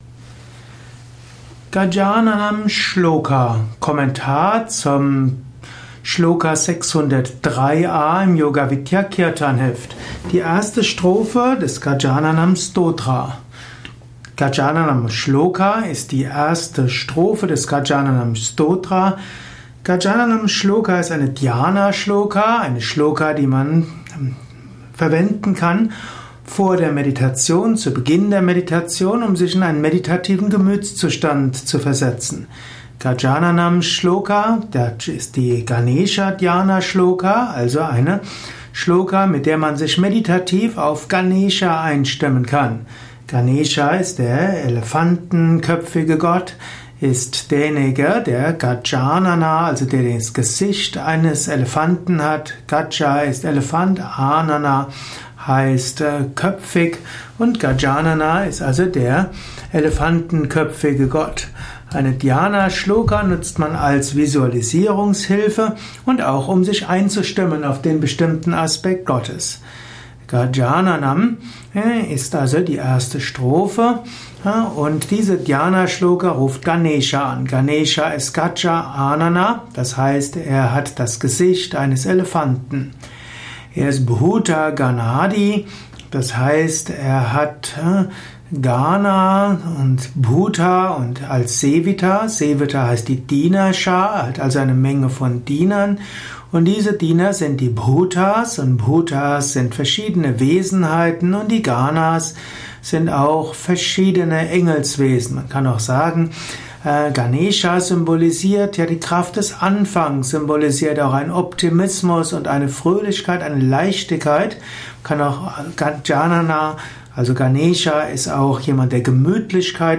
Gajananam Dhyana Shloka 9 Mal rezitiert.